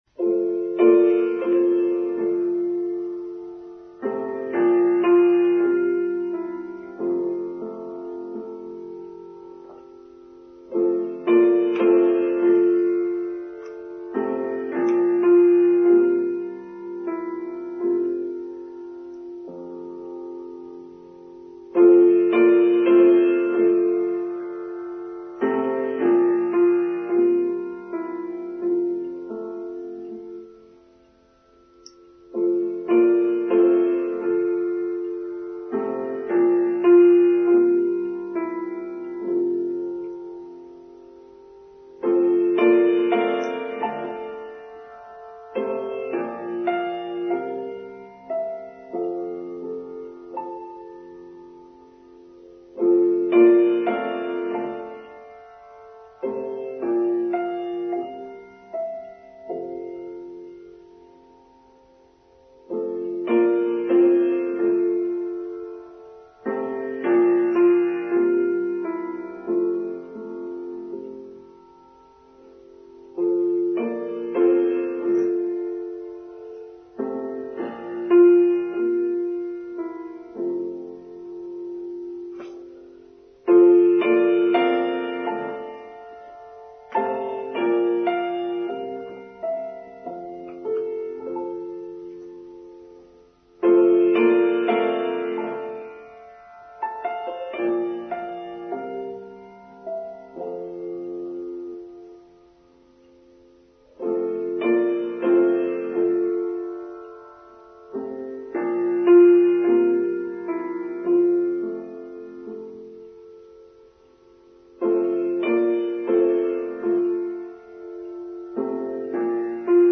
The Power to Choose: Online Service for Sunday 21st May 2023